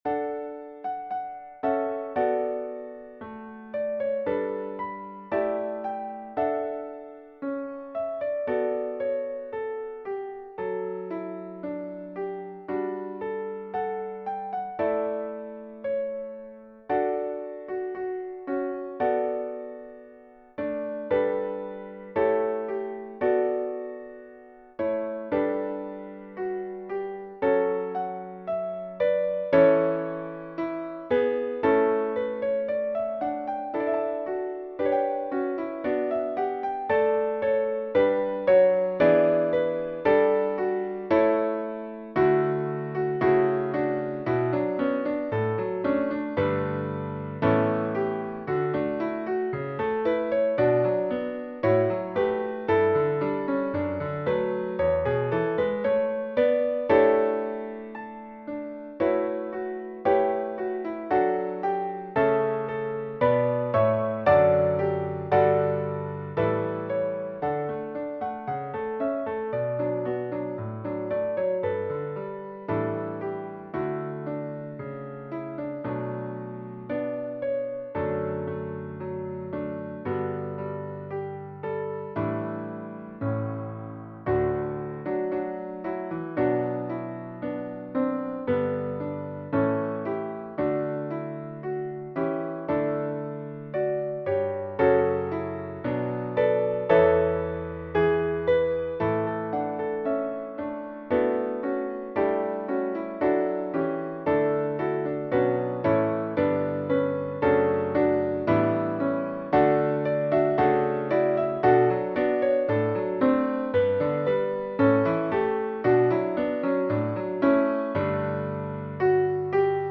This is a SATB hymplicity style hymn that can be used for a pop-up choir.
It has an optional soloist and obligato part.
Voicing/Instrumentation: SATB We also have other 30 arrangements of " I Believe in Christ ".
Choir with Soloist or Optional Soloist